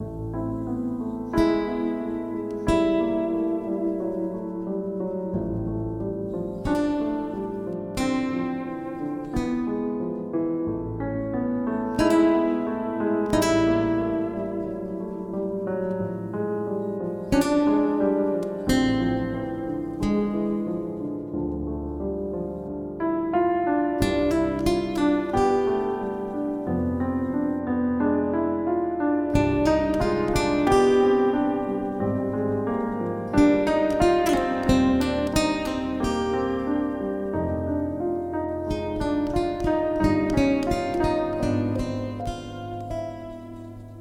• Качество: 192, Stereo
грустные
инструментальные
электрогитара
фортепиано
грустная мелодия